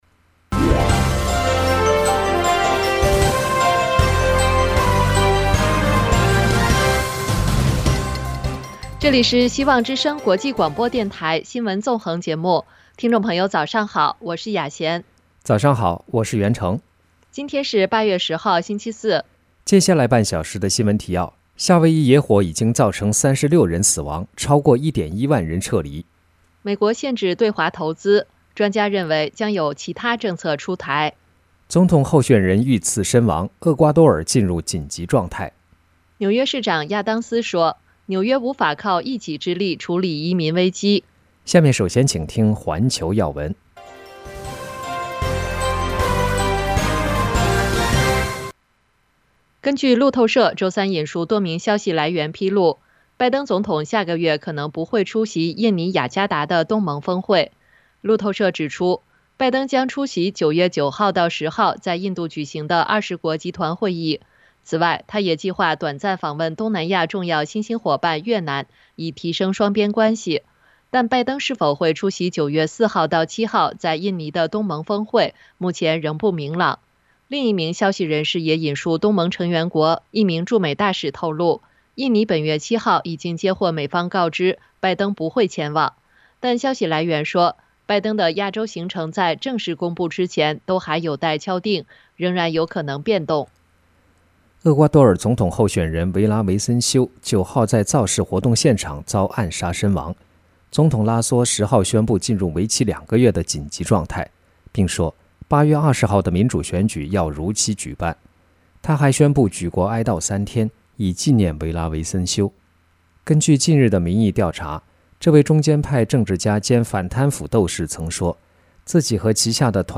白宫纪念《芯片法案》一周年 超过460家公司正在申请补贴【晨间新闻】